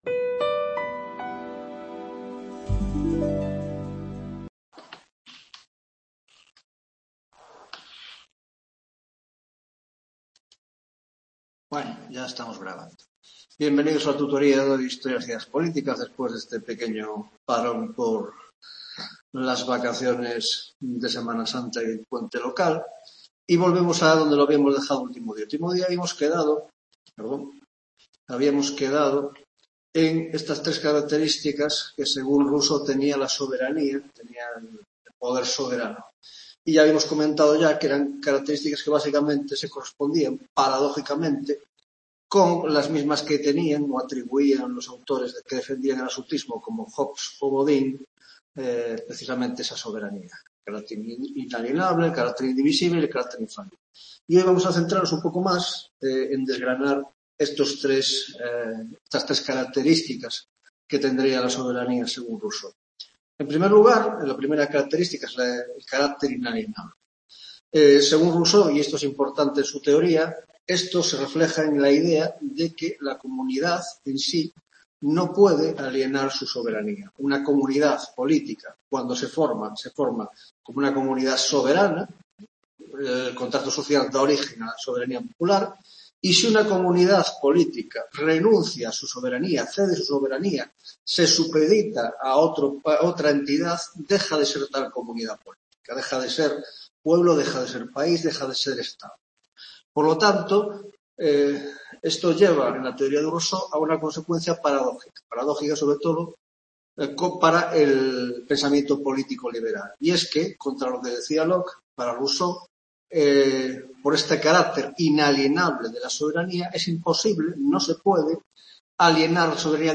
6º Tutoría de Historia de las Ideas Políticas